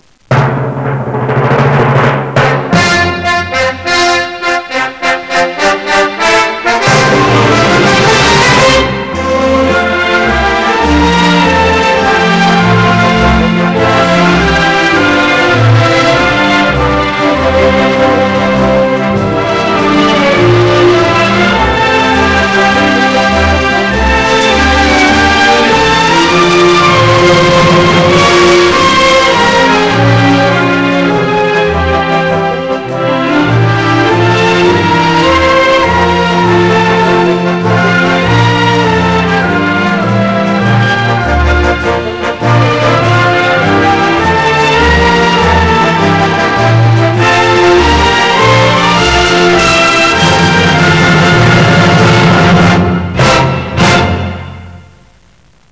El himno oficial de la Comunidad
composición musical sin letra